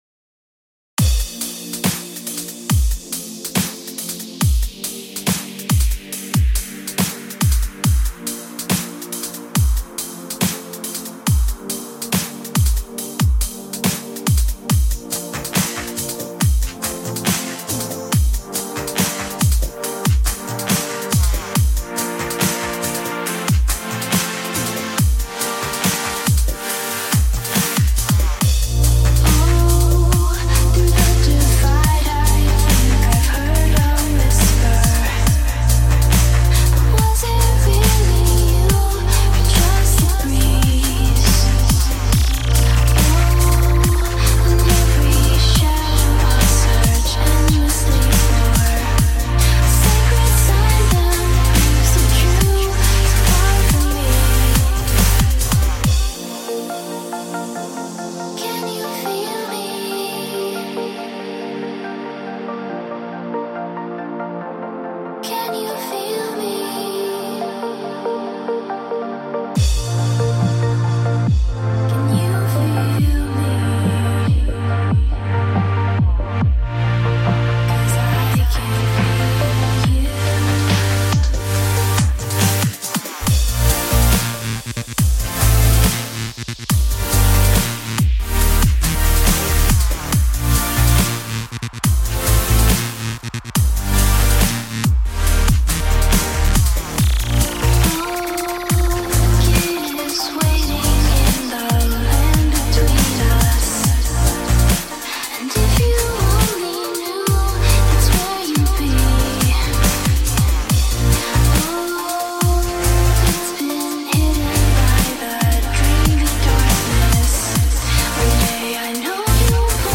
simple remix